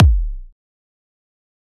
EDM Kick 35.wav